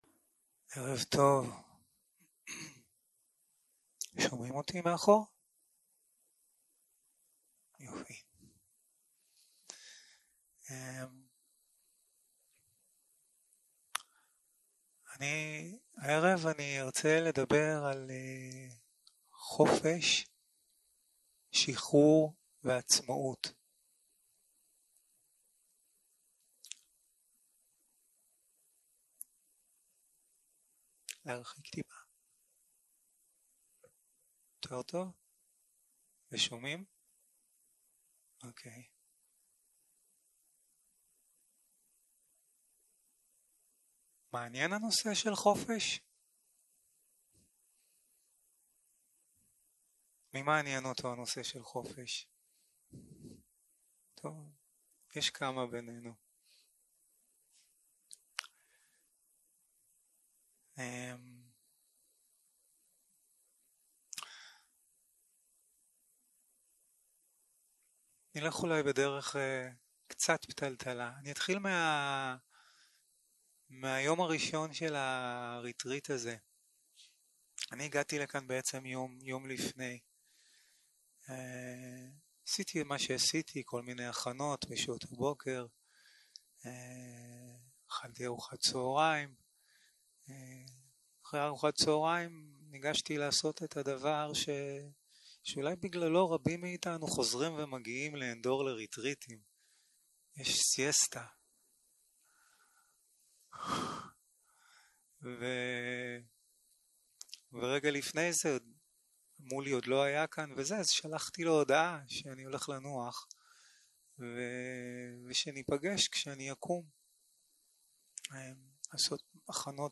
יום 5 - הקלטה 14 - ערב - שיחת דהרמה - חופש, שחרור ועצמאות